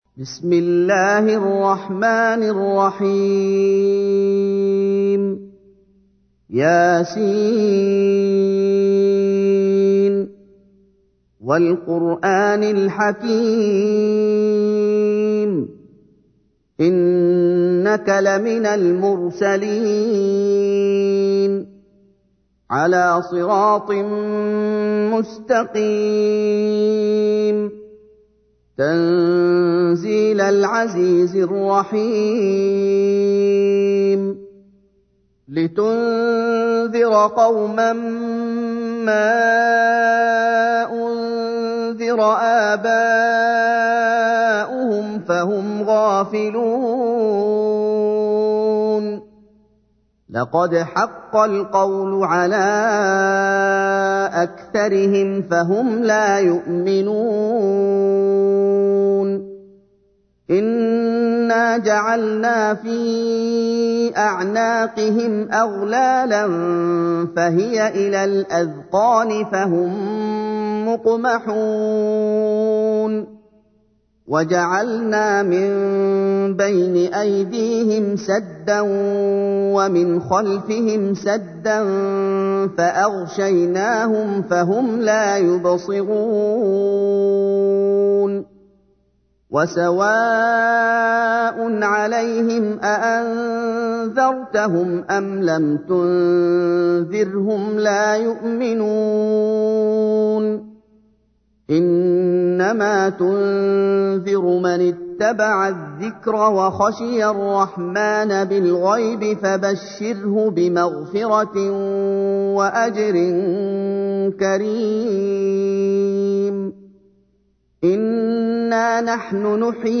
تحميل : 36. سورة يس / القارئ محمد أيوب / القرآن الكريم / موقع يا حسين